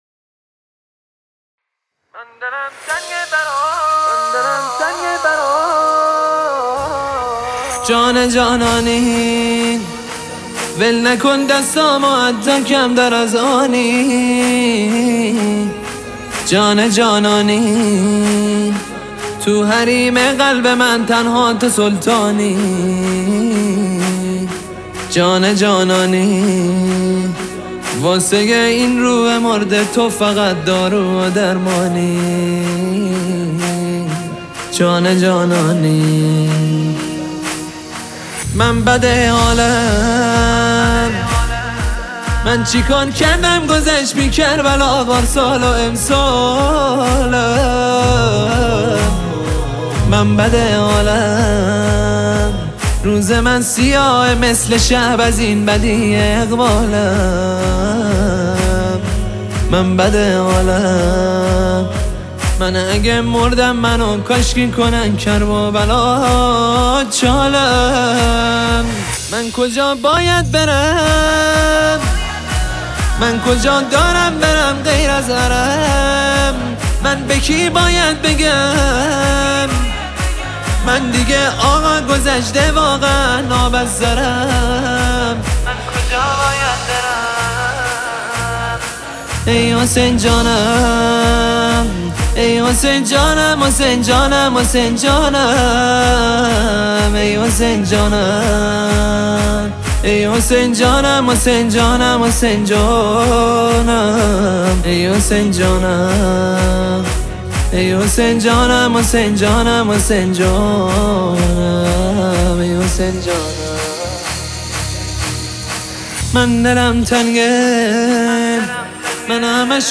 دانلود نوحه با کیفیت 320 دانلود نوحه با کیفیت 128